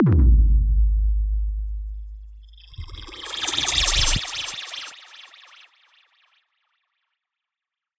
描述：断裂，填充效果。修改了踢鼓和我用尺子划过桌子边缘发出的声音。拉长了尺子的声音并加入了相位。